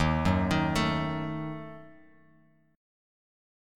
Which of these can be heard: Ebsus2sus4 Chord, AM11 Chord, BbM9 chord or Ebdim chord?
Ebdim chord